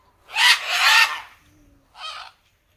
parrot-squawk.ogg